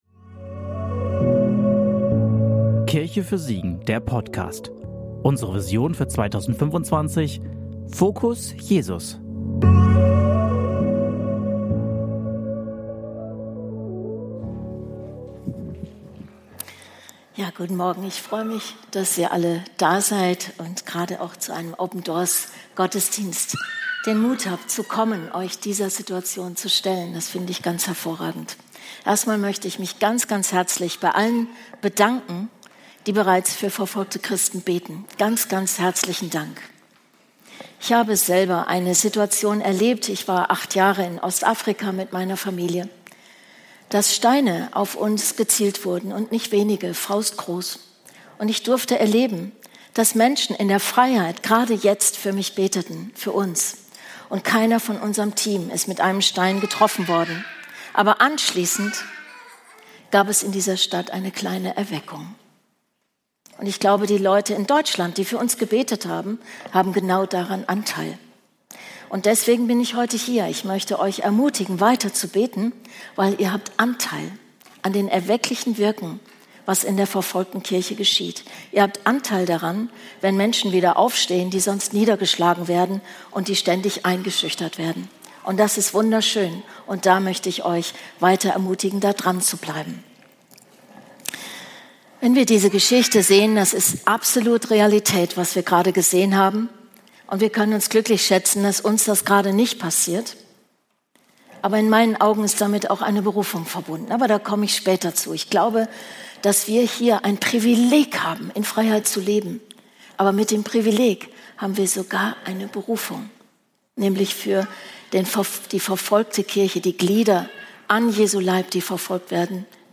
Predigt vom 26.10.2025 in der Kirche für Siegen